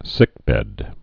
(sĭkbĕd)